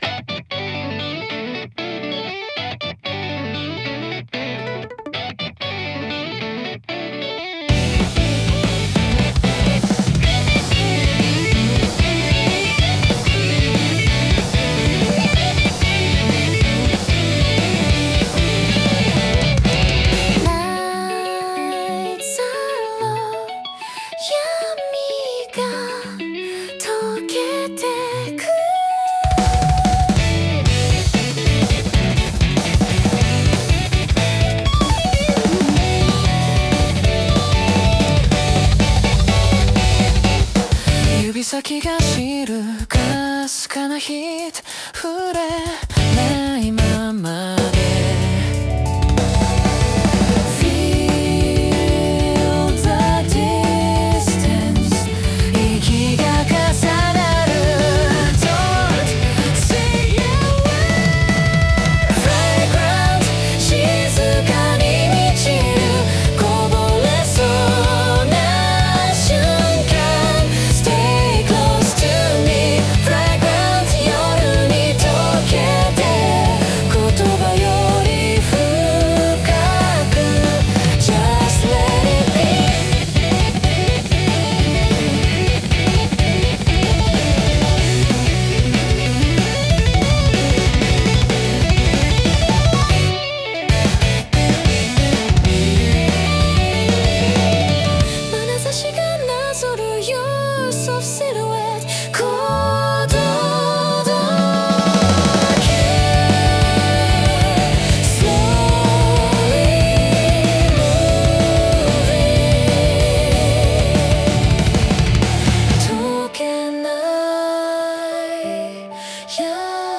女性ボーカル
イメージ：J-ROCK,シティーROCK,女性ボーカル,かっこいい,切ない